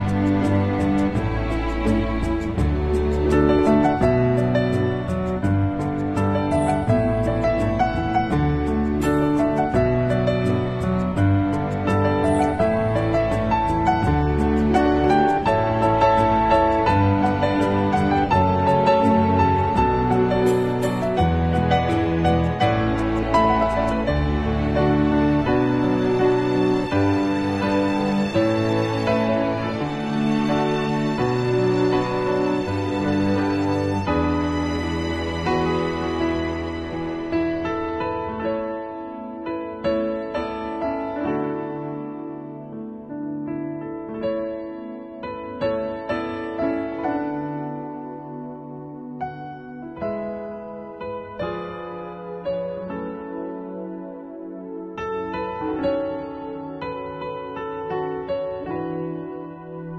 Tobu 8000 Series On Tobu Sound Effects Free Download